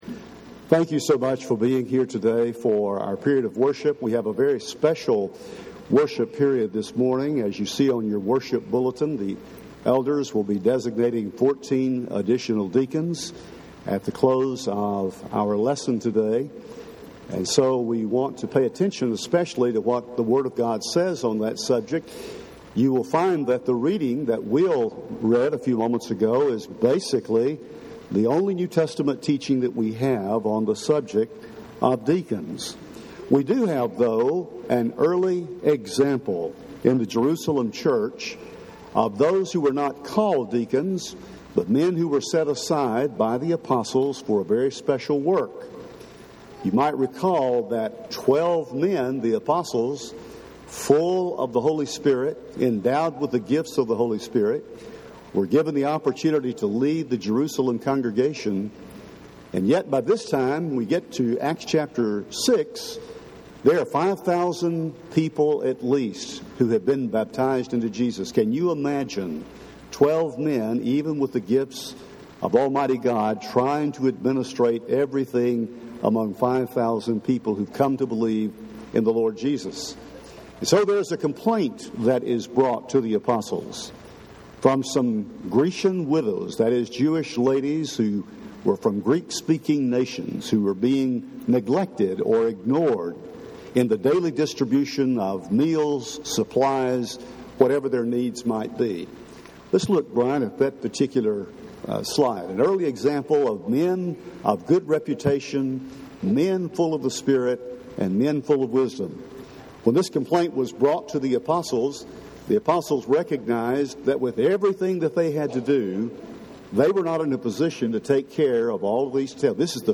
Service